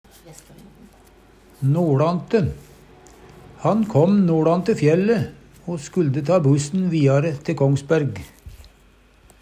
DIALEKTORD PÅ NORMERT NORSK noLante frå nord, nordafrå Eksempel på bruk Han kom noLante fjælle o skulde ta bussen viare te Kongsberg.